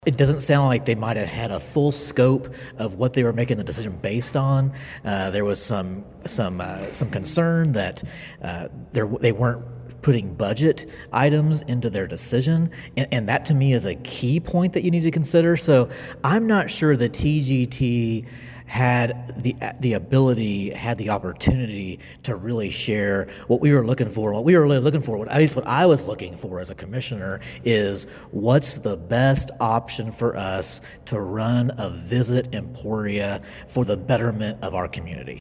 Commissioner Tyler Curtis also had an issue with the rubric, noting it did not account for budgets. During an interview with KVOE News, Curtis stated he felt the committee was making a recommendation without all the pieces necessary to do so.